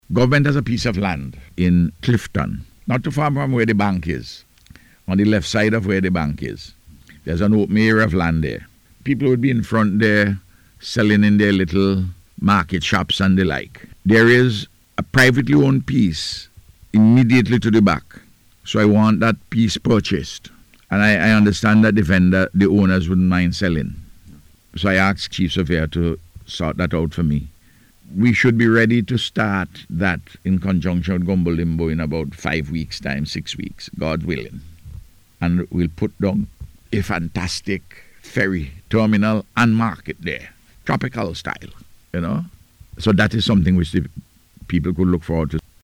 The Prime Minister made the announcement on NBC Radio on Wednesday, as he provided an update on the ongoing reconstruction efforts in the aftermath of Hurricane Beryl.